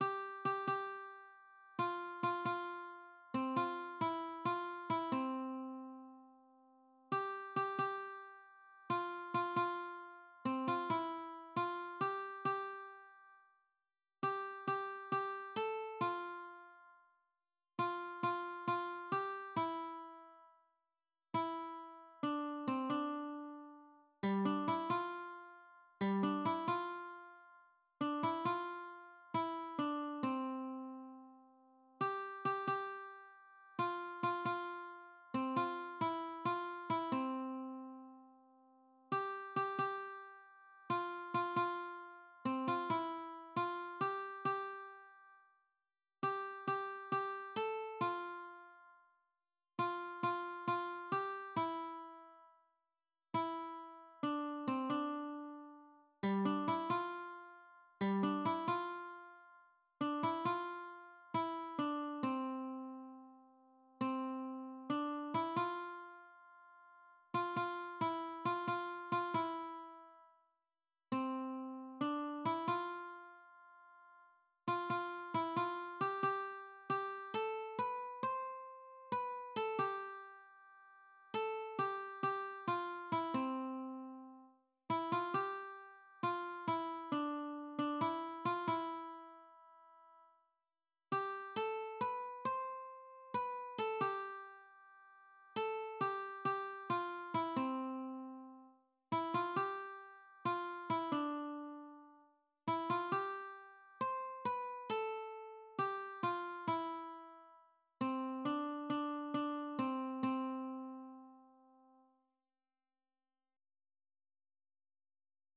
Műfaj magyar könnyűzenei dal